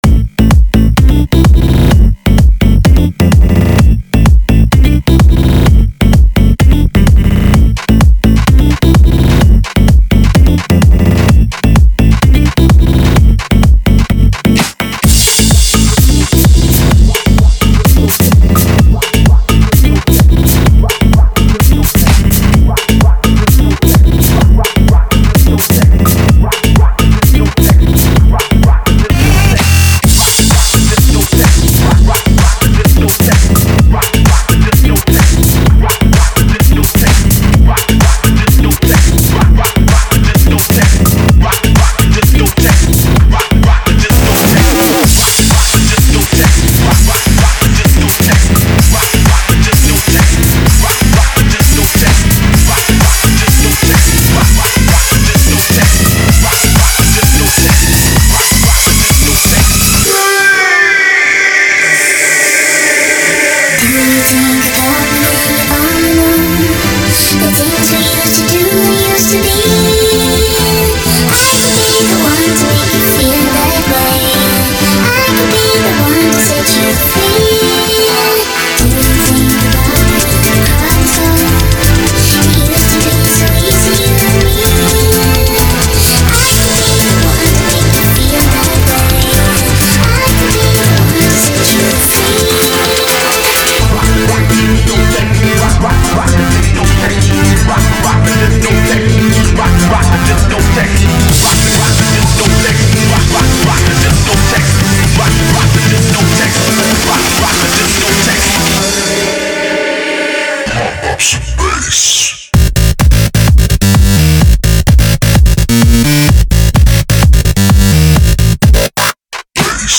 (Ремикс)
Качество урезано до 256....